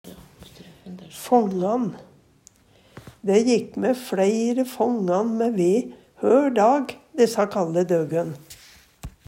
fångan - Numedalsmål (en-US)